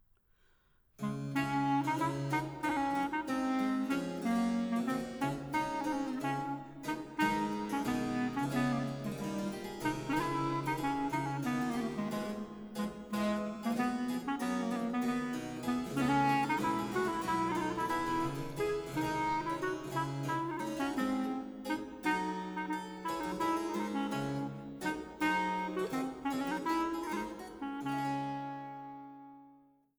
Suite g-Moll für Blockflöte (Tenor-Chalumeau) und B. c.